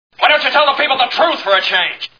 Mr Smith Goes to Washington Movie Sound Bites